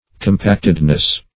Compactedness \Com*pact"ed*ness\, n. A state of being compact.